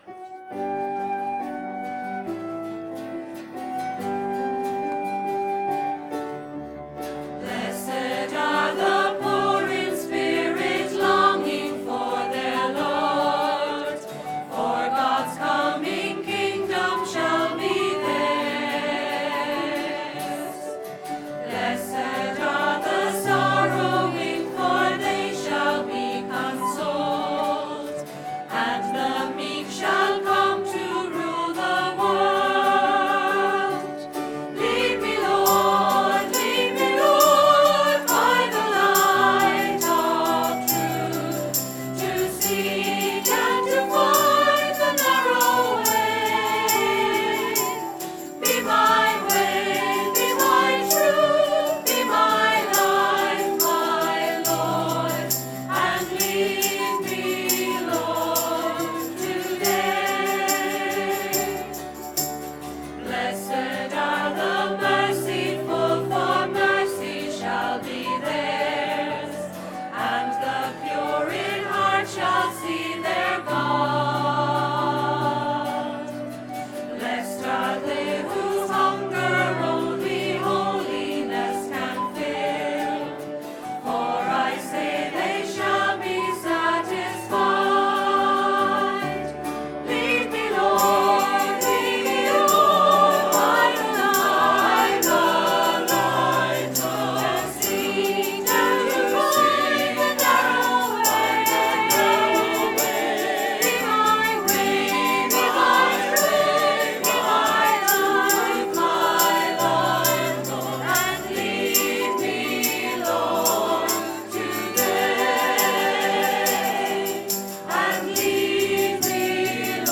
bongo drums
(Here’s a not-so-sneaky recording of the Folk Group singing “Lead Me, Lord” at Sunday mass this weekend!)